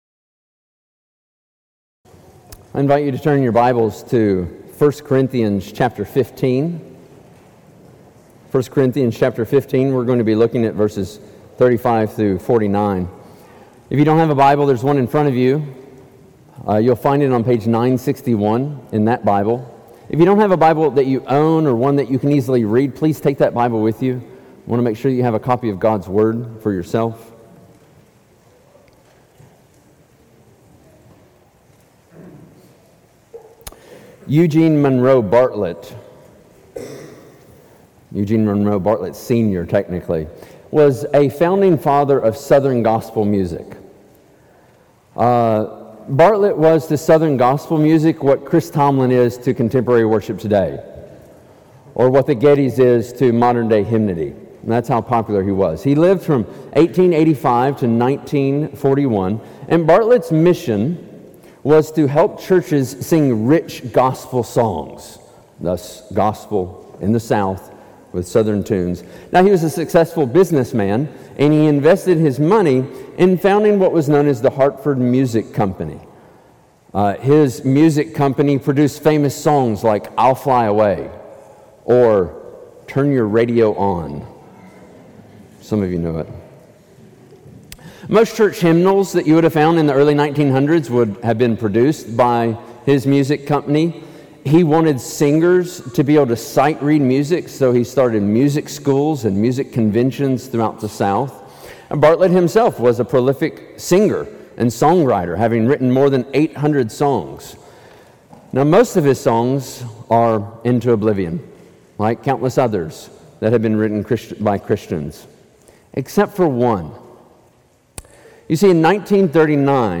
Six Sermons on Glorification